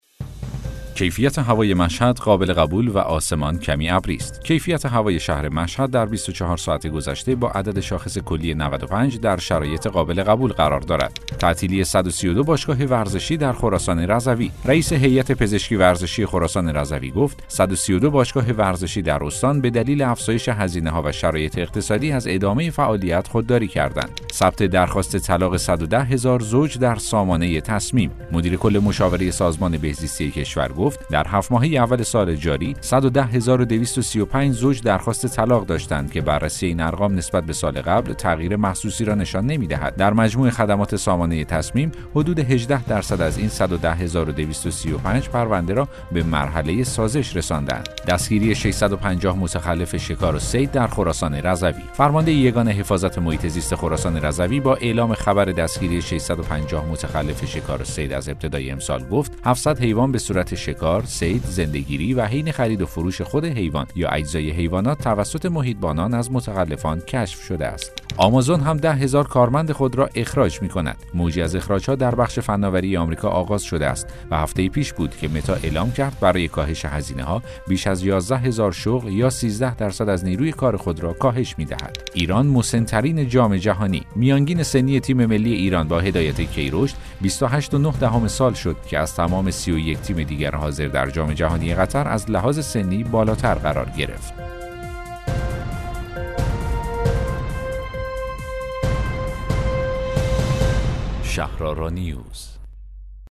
اخبار صوتی - سه شنبه صبح ۲۴ آبان ۱۴۰۱